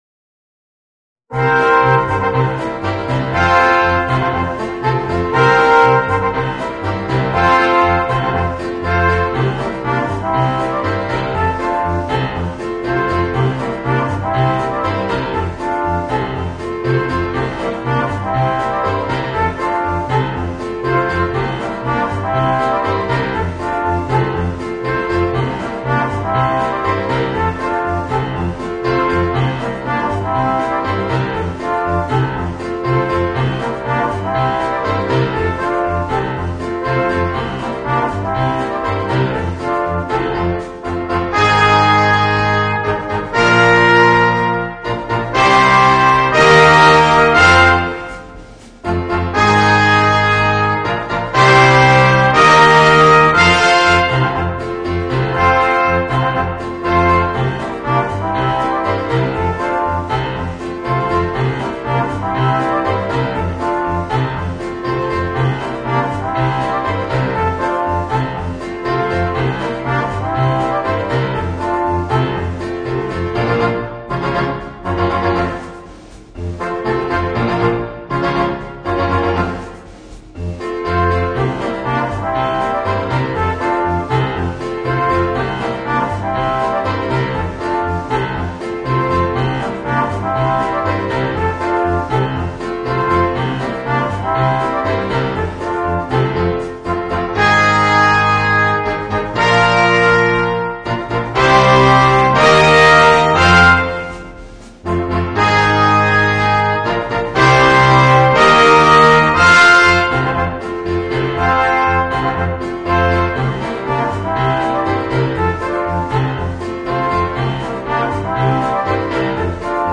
Voicing: Small Ensembles